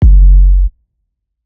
Timbo Bass.wav